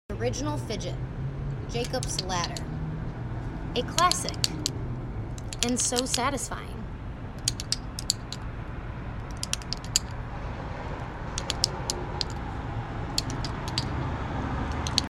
Keep it old school fidget sound effects free download